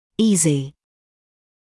[‘iːzɪ][‘иːзи]лёгкий, несложный, простой